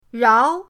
rao2.mp3